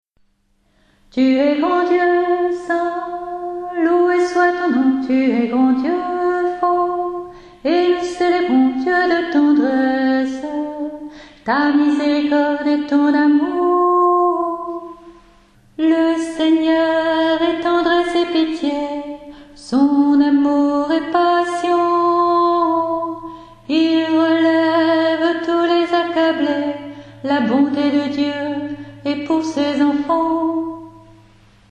Voix chantée (MP3)COUPLET/REFRAIN
ALTO